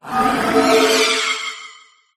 dragapult_ambient.ogg